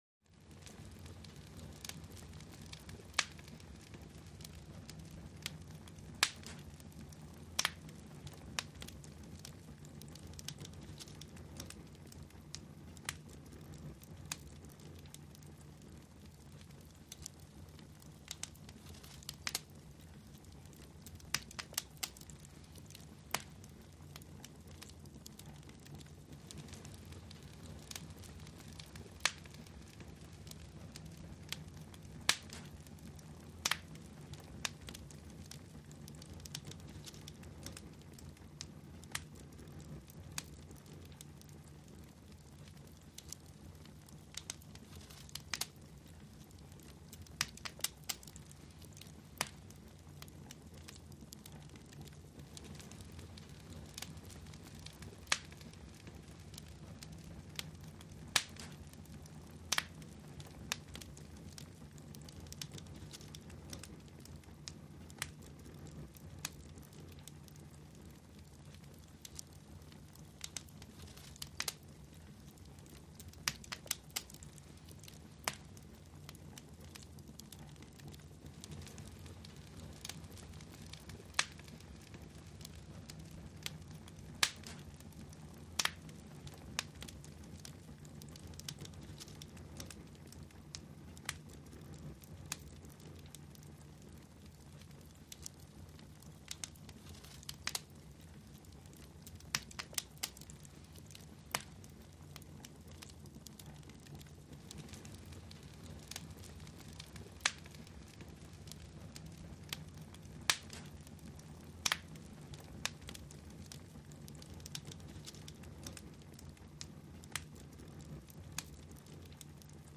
Fire; Small Wood, With Roar, Hiss And Crackle.